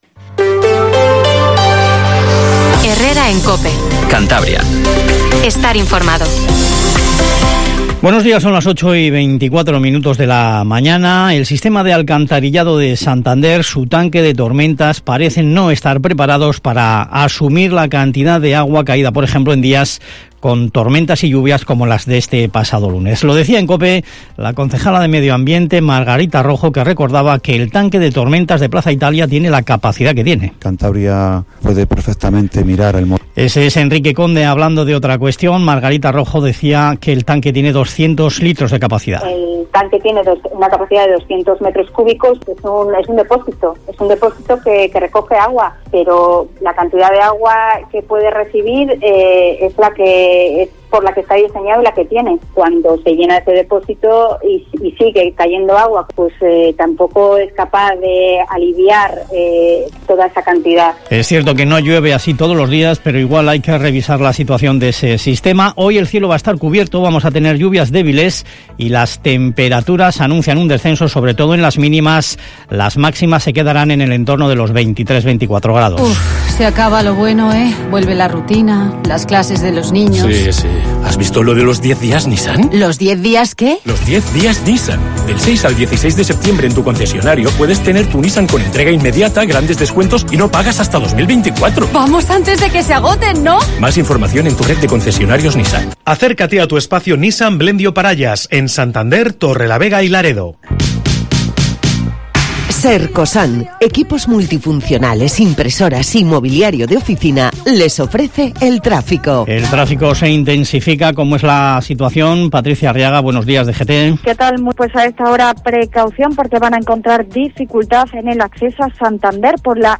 Informativo HERRERA en COPE CANTABRIA 08:24